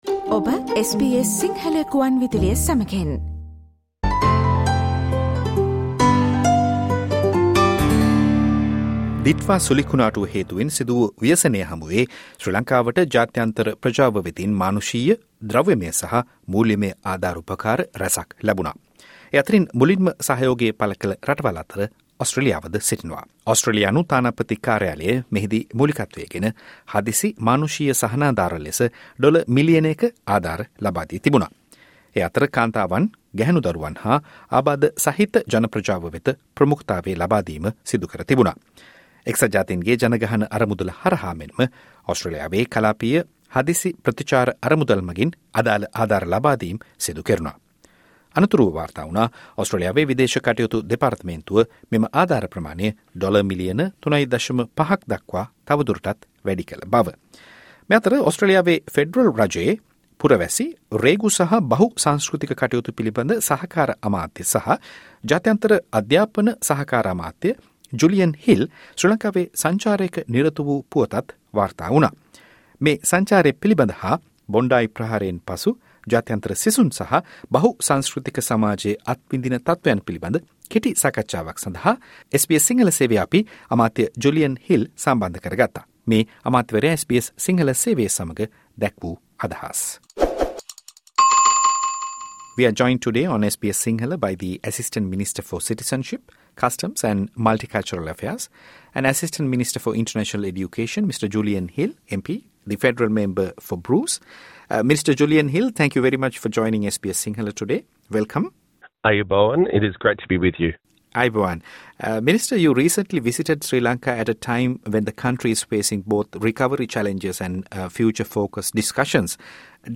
‘ඕස්ට්‍රේලියාවේ සිටින සියලුම විදේශීය සිසුන්ගේ ආරක්ෂාව සහතිකයි’ - සහකාර අමාත්‍ය ජුලියන් හිල් SBS සිංහල සේවය සමඟ පැවති සාකච්ඡාව